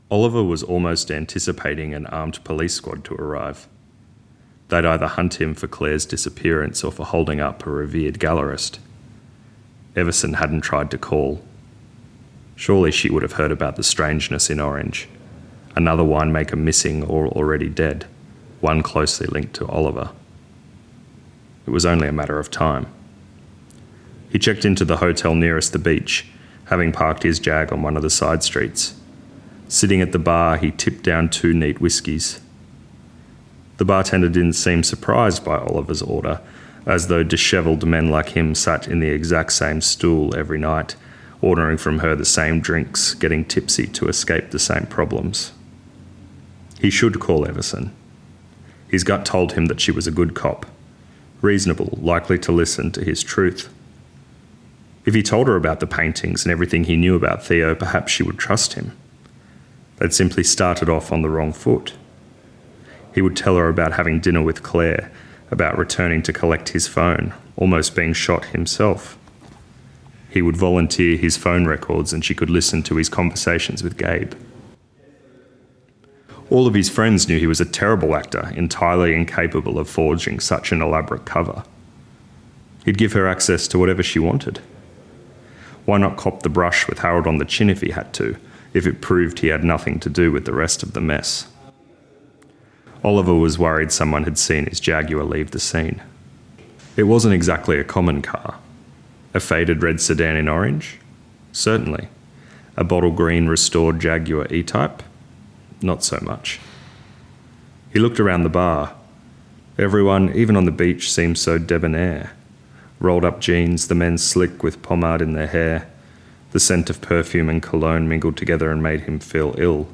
Recorded at BAD Crime Writers Festival Sydney 2023